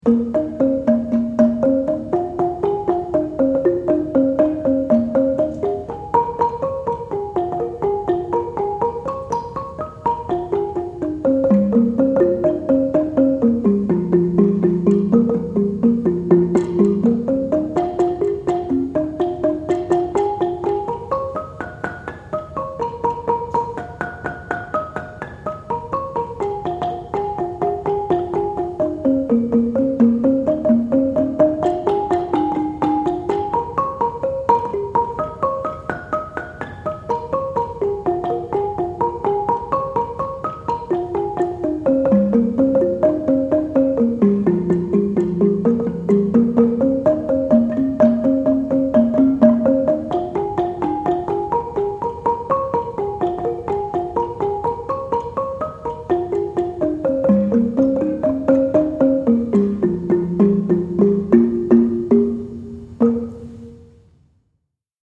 Gambang
The gambang is a wooden xylophone with seventeen to twenty-one keys with the range of two octaves or more.
The gambang is played with two disc-type beaters that have long, somewhat flexible horn handles. Most of the time, the gambang plays in octaves (gembyang).
Because of this fact and the elaborate and high speed of the gambang playing style, this instrument is considered one of the important instruments in the ensemble.
Javanese Gamelan